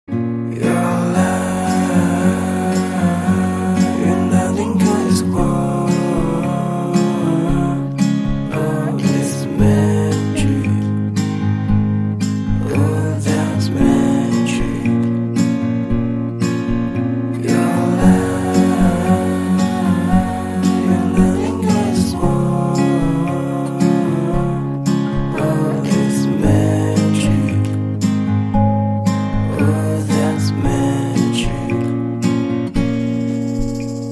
欧美歌曲